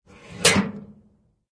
mailbox_close_2.ogg